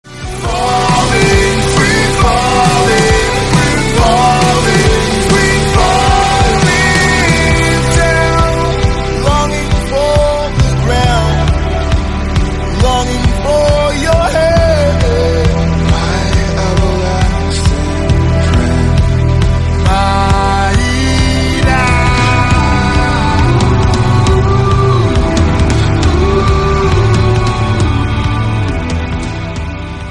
free-fall-6.mp3